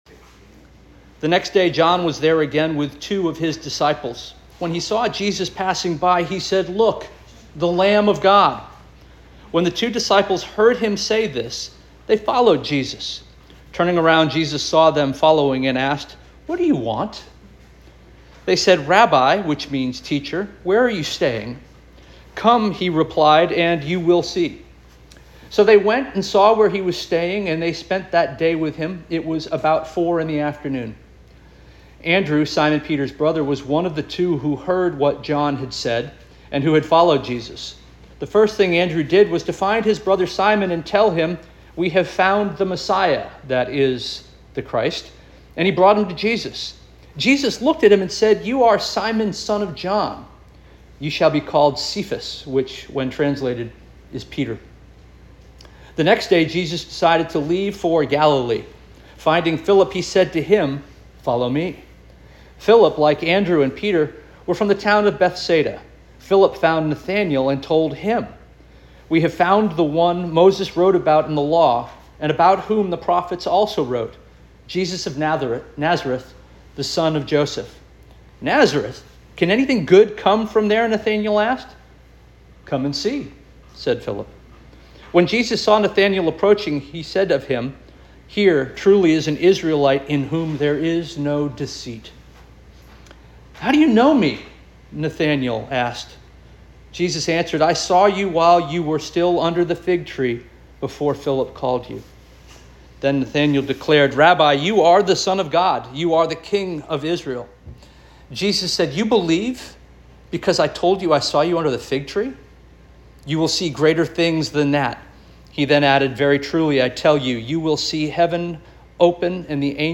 August 17 2025 Sermon